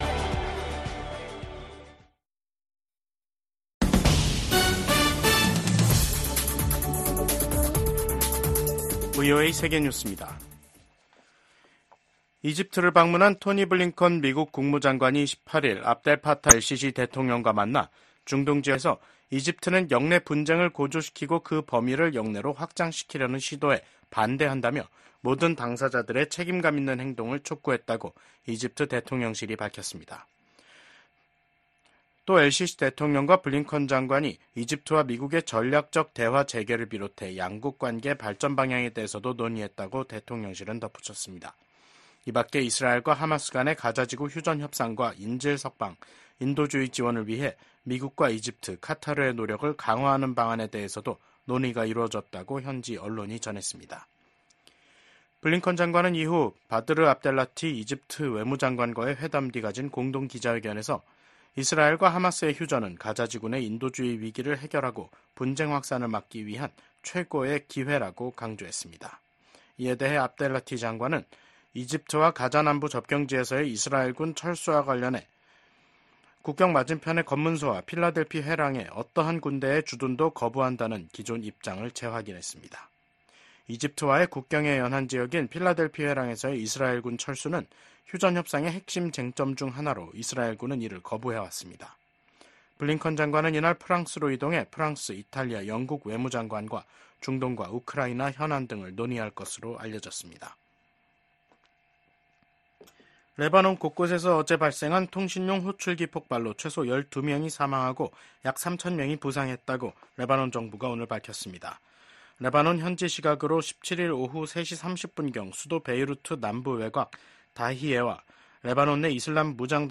VOA 한국어 간판 뉴스 프로그램 '뉴스 투데이', 2024년 9월 18일 3부 방송입니다. 북한이 한반도 시각 18일 탄도미사일 여러 발을 발사했습니다. 미국은 이번 발사가 다수의 유엔 안보리 결의 위반이라며 규탄했습니다. 한미연합사령관 지명자가 북한의 핵과 미사일 역량 진전을 최대 도전 과제로 지목했습니다.